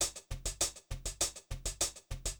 Сэмплы ударных в стиле Funk — Bop A
Тут вы можете прослушать онлайн и скачать бесплатно аудио запись из категории «70's Funk».